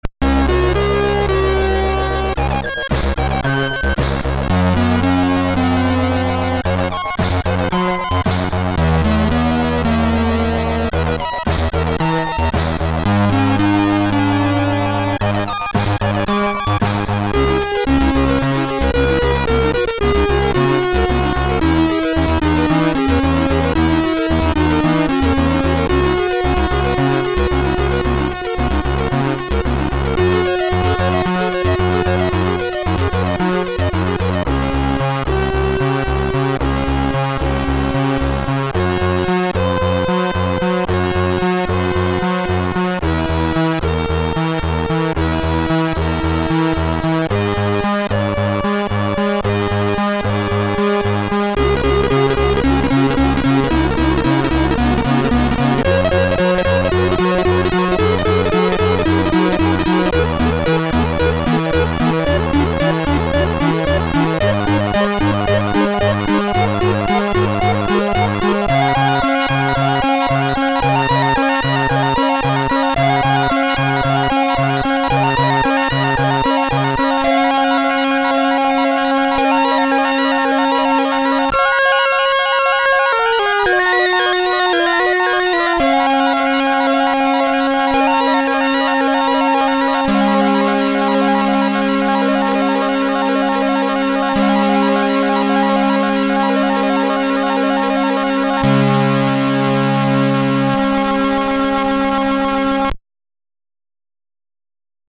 Sounds very spacey, orchestral  and atmospheric.